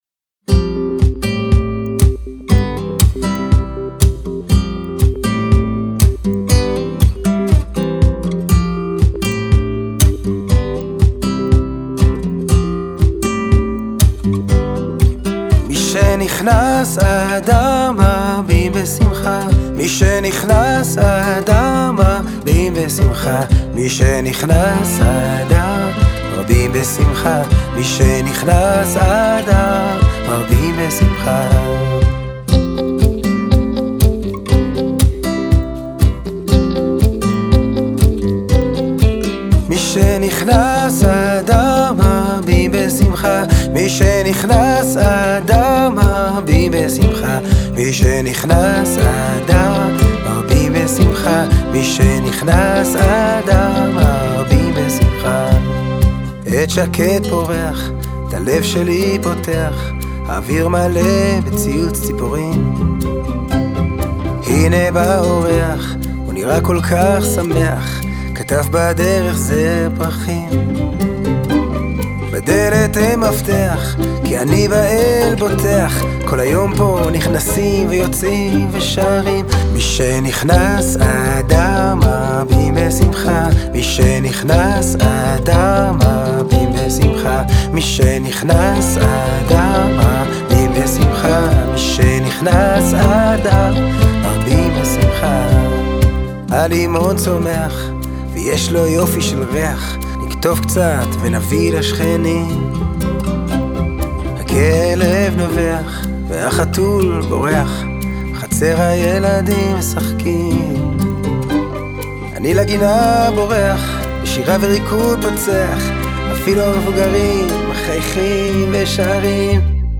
די שאנטית, רגועה, אקוסטית.